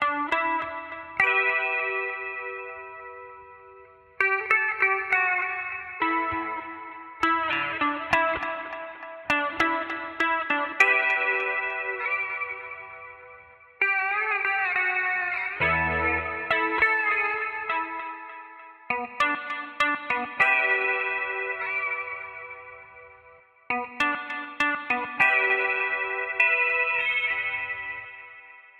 描述：爵士、原声、融合、流行等。
Tag: 100 bpm Blues Loops Guitar Electric Loops 4.85 MB wav Key : Unknown